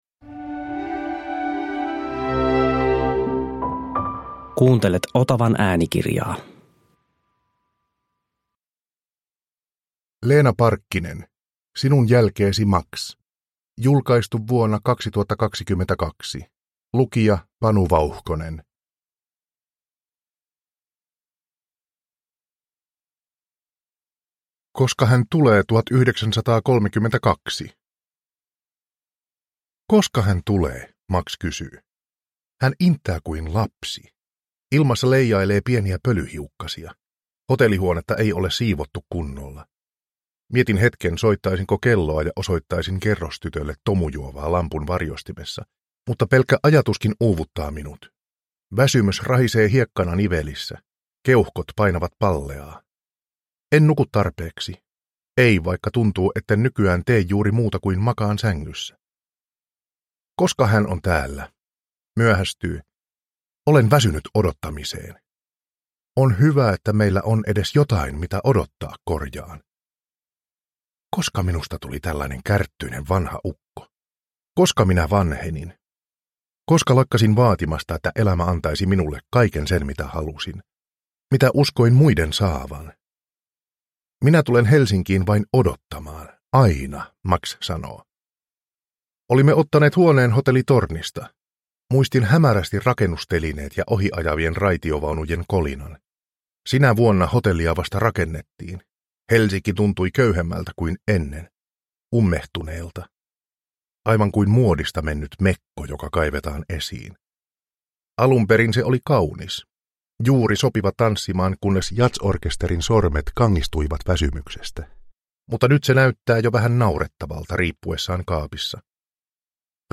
Sinun jälkeesi, Max – Ljudbok – Laddas ner